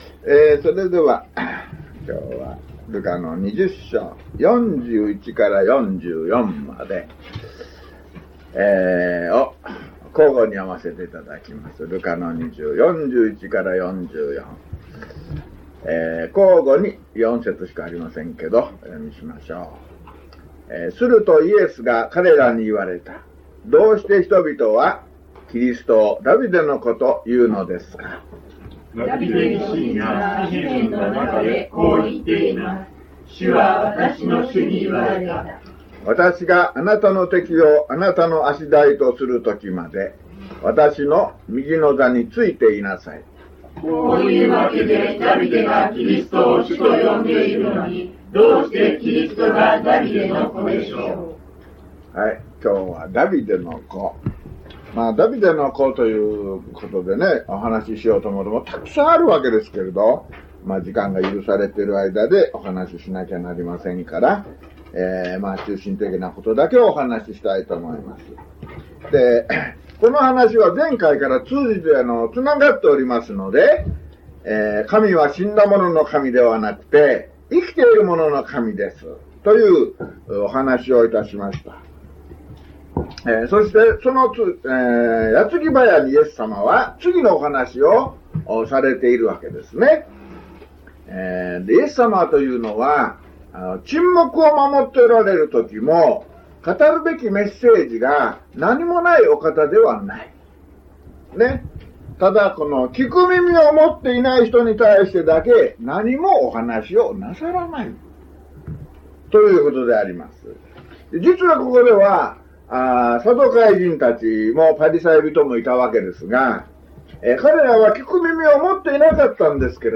luke157mono.mp3